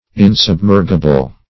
Search Result for " insubmergible" : The Collaborative International Dictionary of English v.0.48: Insubmergible \In`sub*mer"gi*ble\, a. Not capable of being submerged; buoyant.